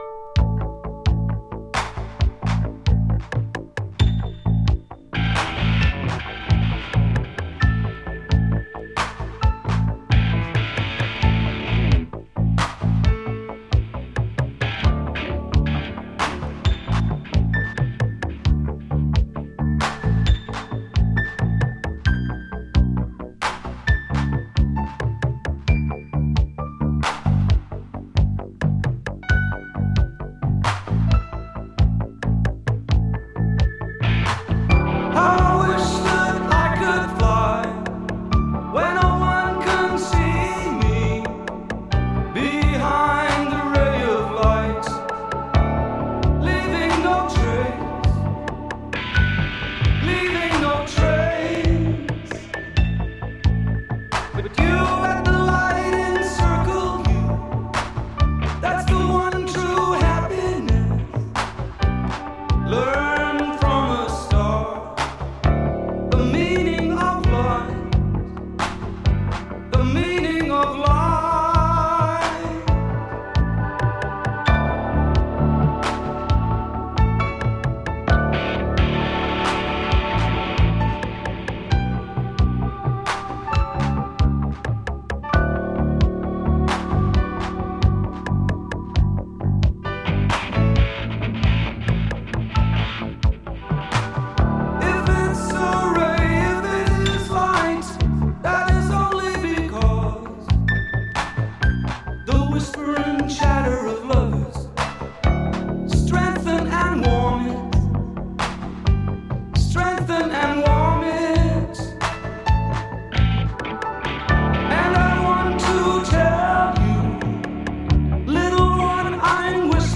オーストラリア産オブスキュア・シンセポップ！
スローモーなシンセ・ポップ
【SYNTH POP】【A.O.R.】